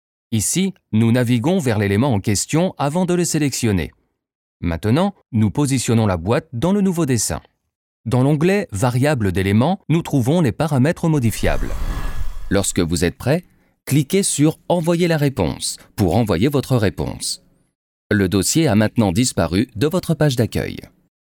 Démos E-learnings
Les démos sur cette page sont des extraits de projets réels livrés et mixés par les studios respectifs.